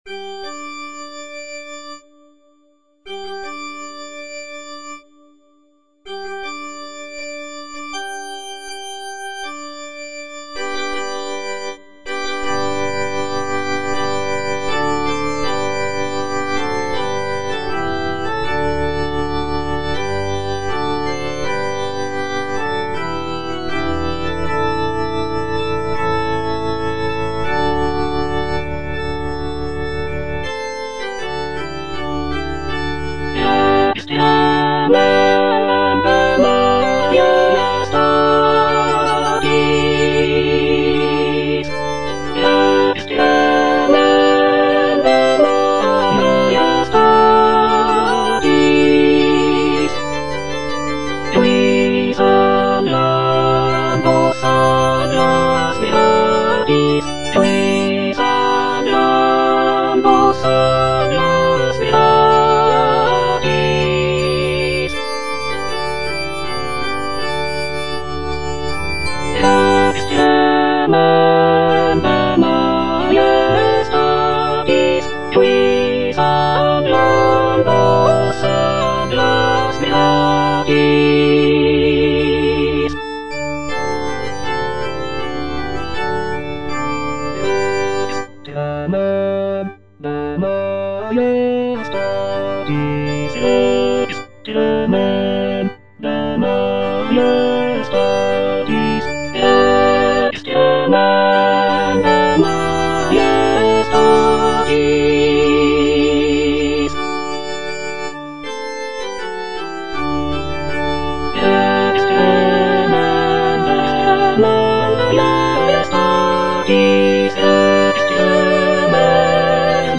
(soprano I) (Emphasised voice and other voices) Ads stop
is a sacred choral work rooted in his Christian faith.